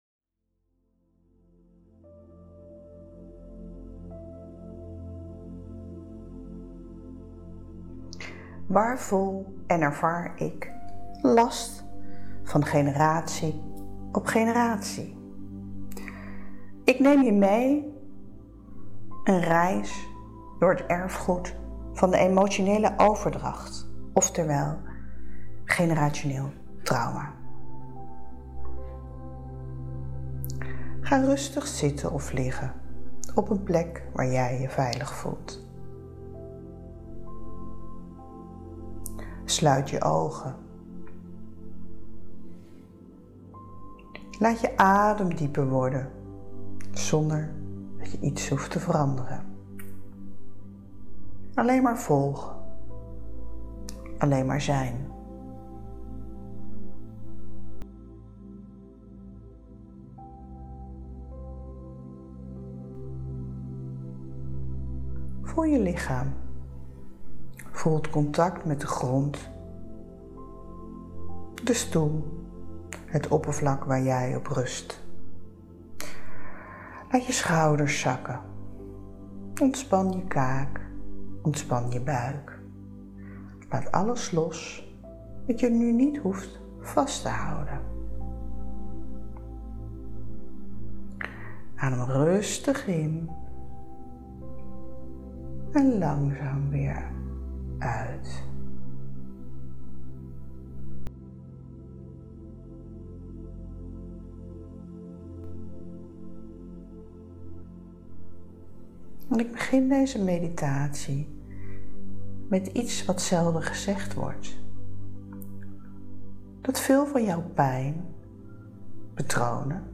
Luister hieronder naar de meditatie en voel wat generationeel trauma in jou aanraakt. (15 minuten)
Generationeel-trauma-meditatie.mp3